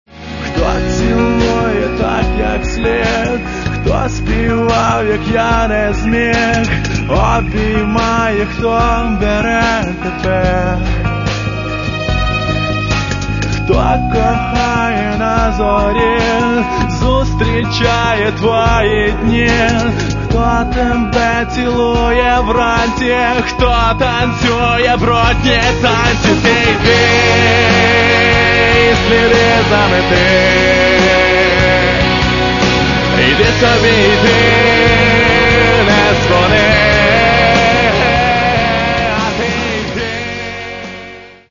Catalogue -> Rock & Alternative -> Light Rock